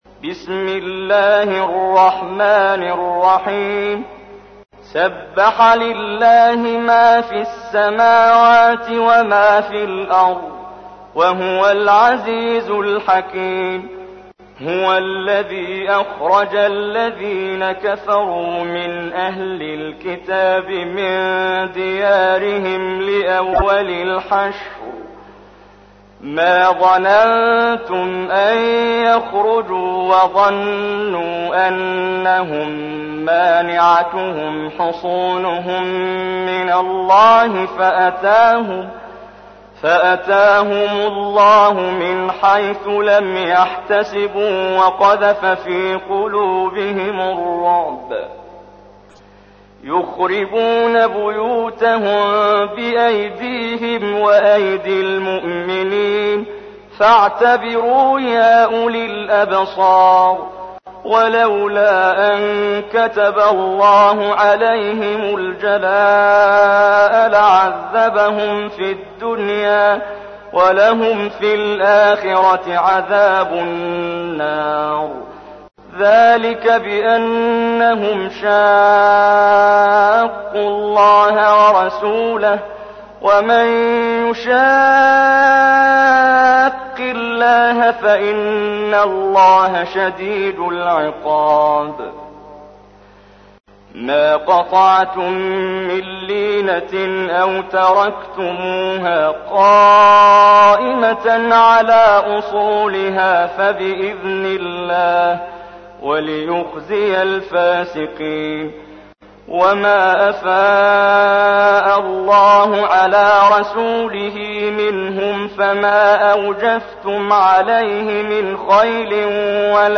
تحميل : 59. سورة الحشر / القارئ محمد جبريل / القرآن الكريم / موقع يا حسين